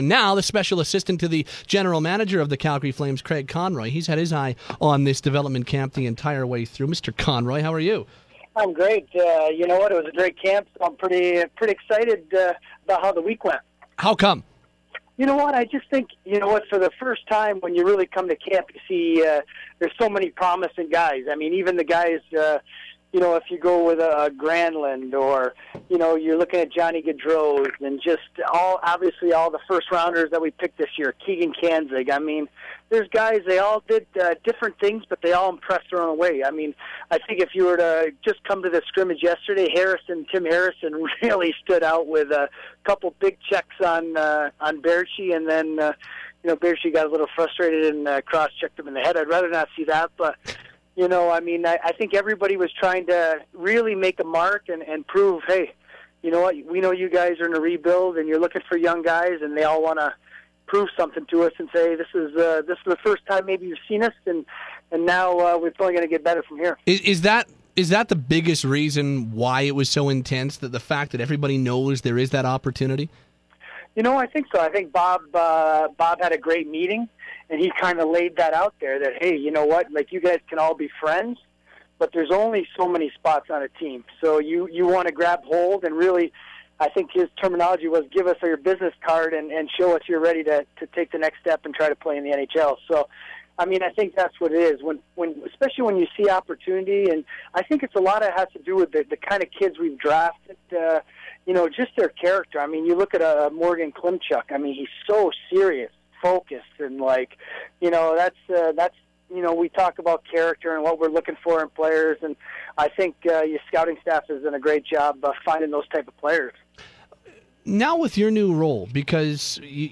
Conroy interview on the Development camp